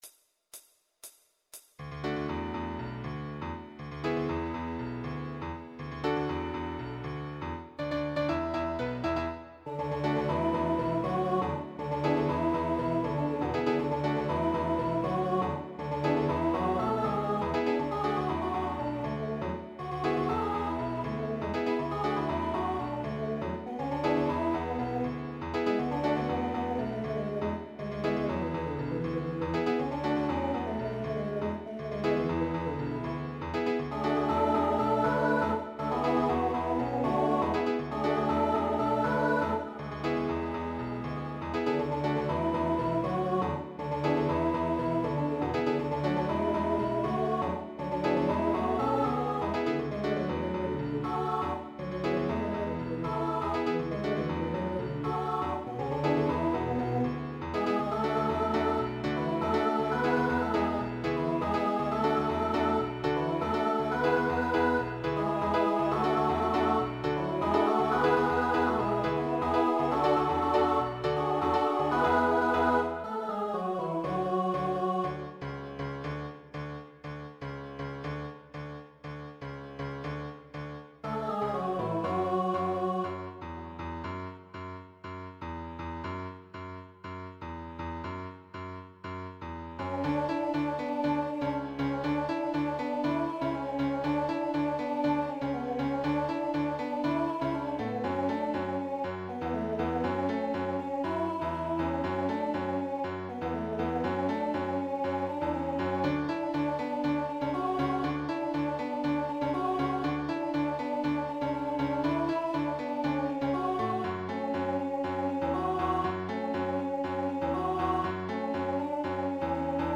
Voicing Mixed Instrumental combo Genre Pop/Dance